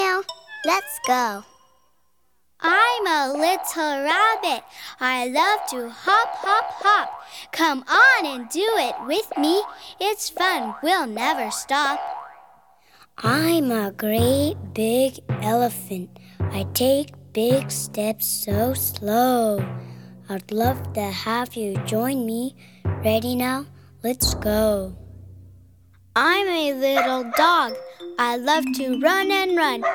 All your favorites are collected in this large selection of 73 traditional songs and fingerplays, sung and chanted by children.